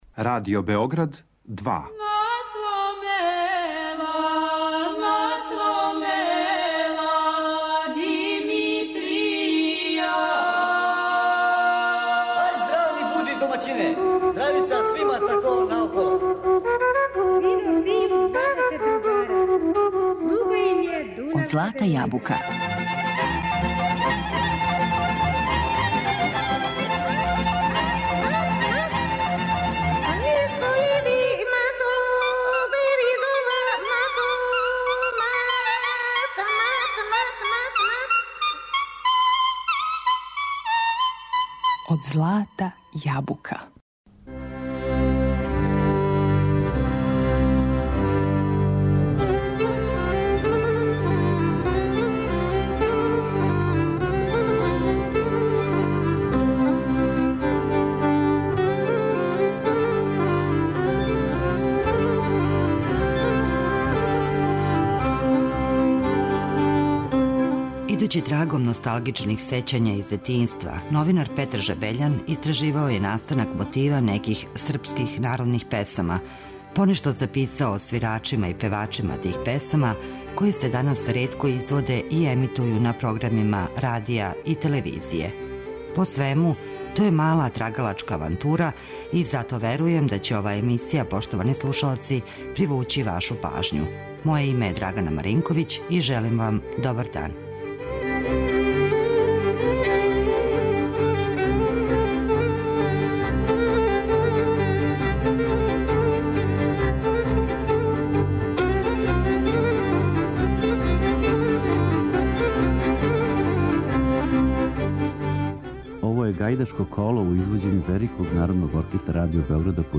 Понешто је и записивао о свирачима и певачима тих песама, а неке од њих чућемо у данашњој емисији.